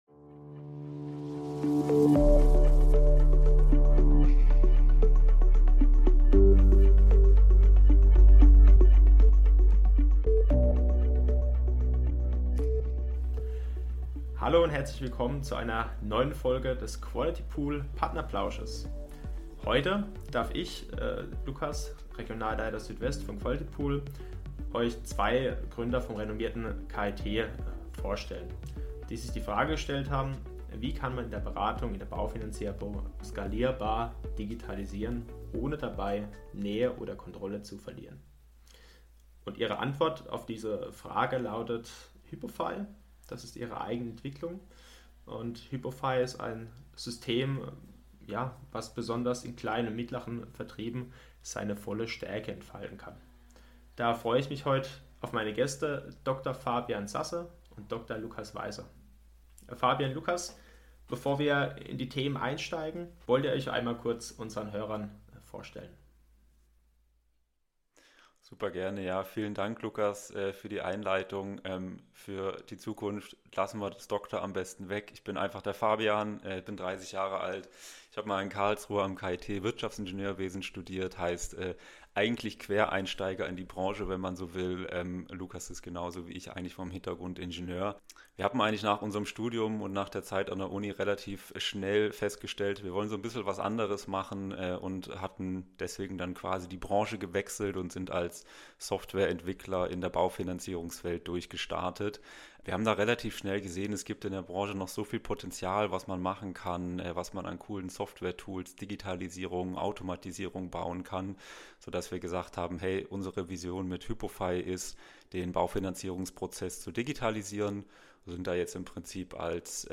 Skalierbar digitalisieren in der Baufinanzierung | Interview mit HYPOFY ~ Qualitypool Partnerplausch Podcast